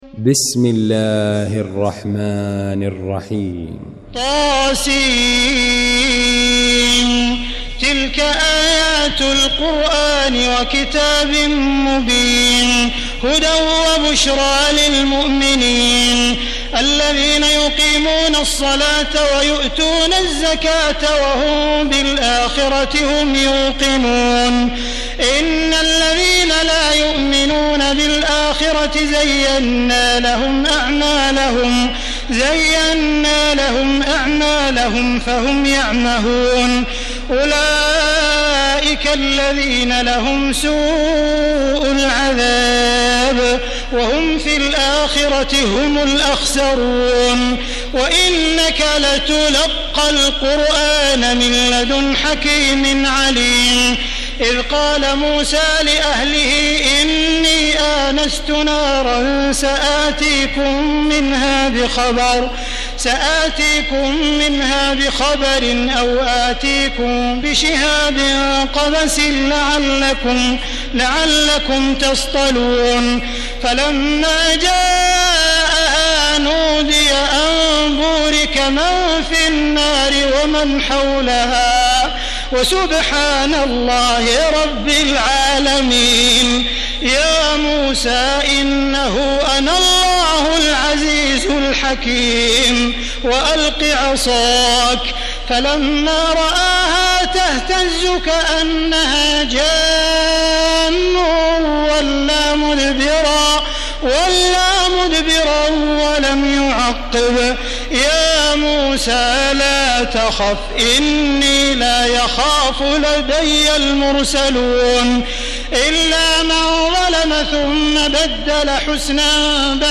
المكان: المسجد الحرام الشيخ: معالي الشيخ أ.د. عبدالرحمن بن عبدالعزيز السديس معالي الشيخ أ.د. عبدالرحمن بن عبدالعزيز السديس فضيلة الشيخ عبدالله الجهني النمل